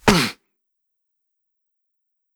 playerHurt1.wav